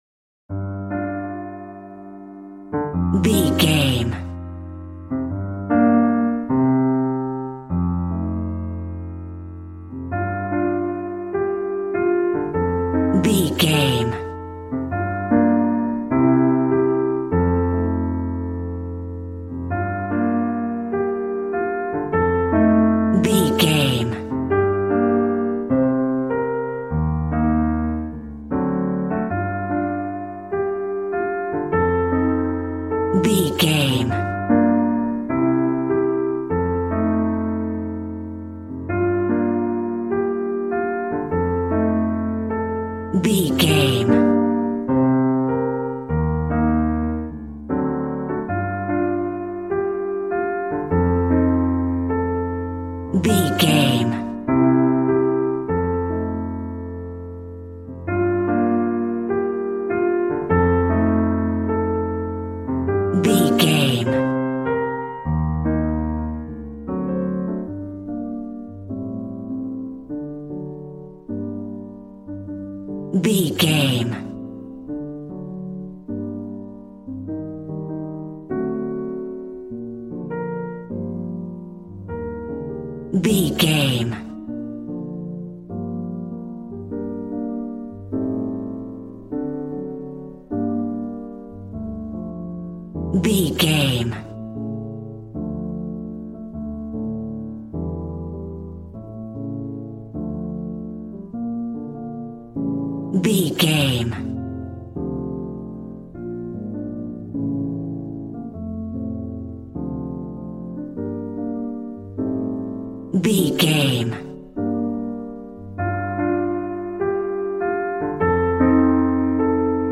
Smooth jazz piano mixed with jazz bass and cool jazz drums.,
Ionian/Major
smooth
piano
drums